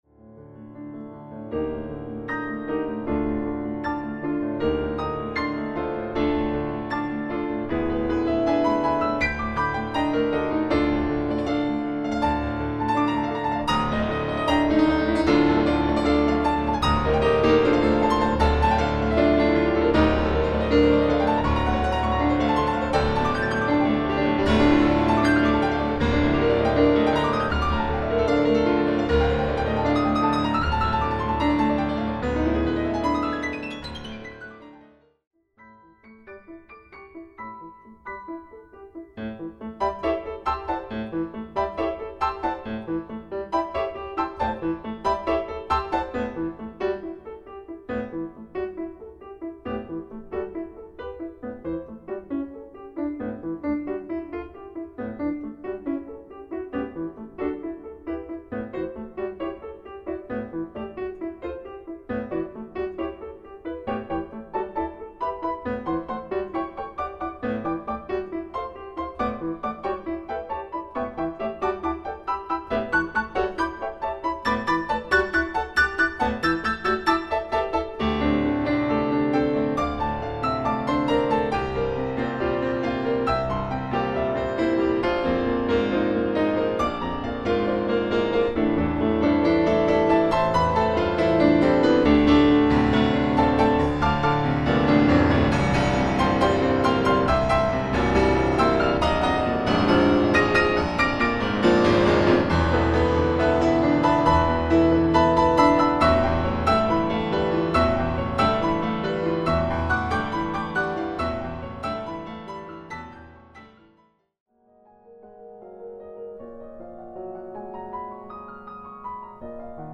Audio preview of all four movements:
Difficulty:  Late Advanced